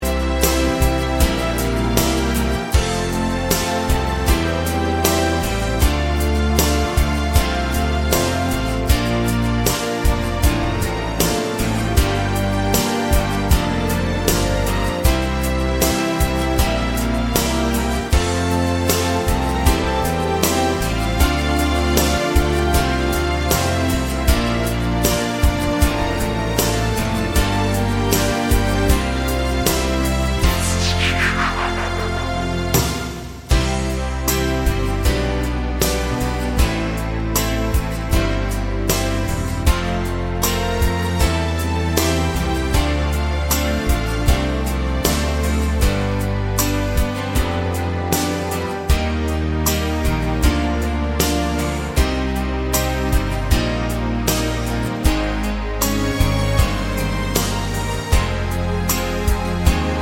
Duet Version Duets 4:04 Buy £1.50